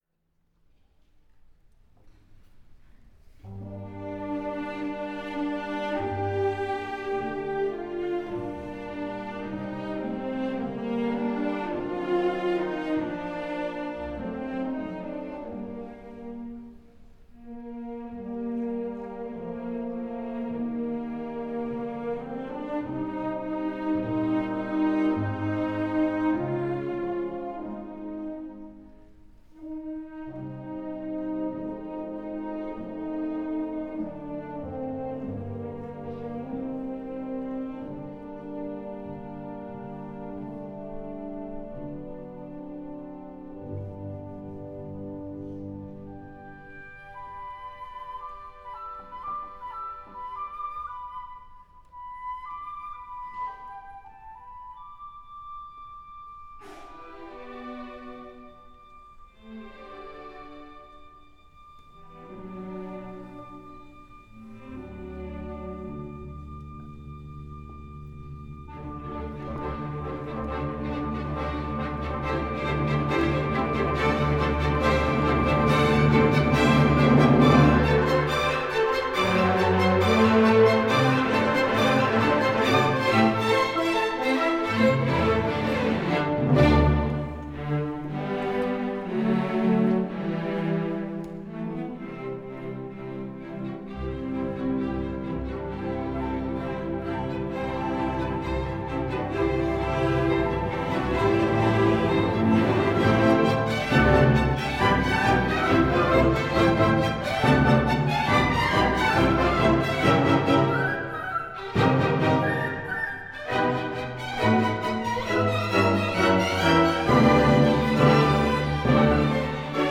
Antonin Dvorak: Sinfonía nº 8 en sol mayor, Op. 88, B. 163 | Euskadiko Orkestra - Basque National Orchestra
Idioma Español Fecha: Viernes, 8 Marzo, 2019 Duración: 36:04 Audio: dvorak080319.mp3 Temporada: Temporada 2018/2019 Audio promocionado: Autor: Antonin Dvorak Categoría: Temporada de abono ¿Pertenece a algún disco?: